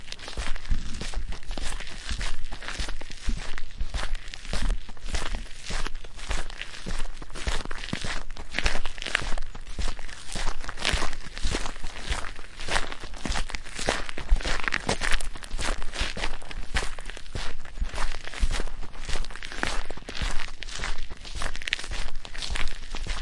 在砾石上行走
描述：在石渣表面的脚步，外面
Tag: 木槌 步骤 现场录音 OWI 散步